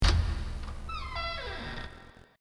door_2.ogg